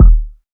Kicks
KICK.96.NEPT.wav